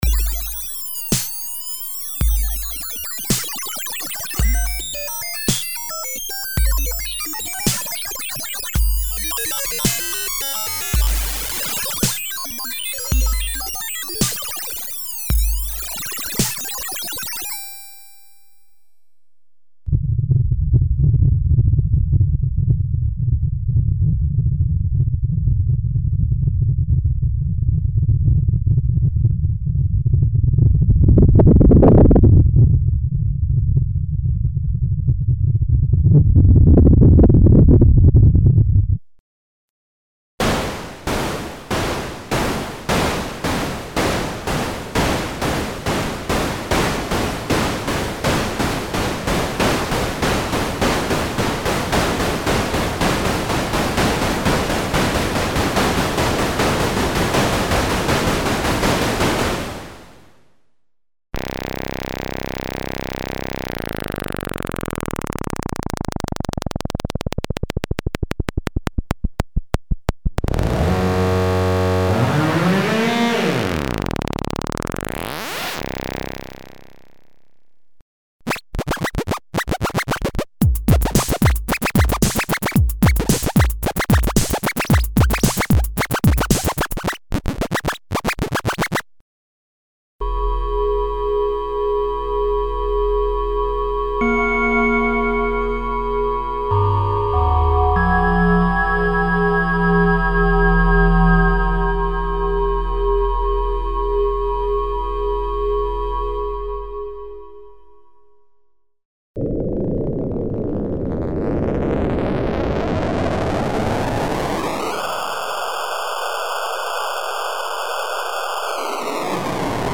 Emulations of vintage digital synthesizers - PPG, DX (FM synthesis) program variations (filtered and specially modulated noises and digital effects).
Info: All original K:Works sound programs use internal Kurzweil K2500 ROM samples exclusively, there are no external samples used.
K-Works - Digital Volume 3 - LE (Kurzweil K2xxx).mp3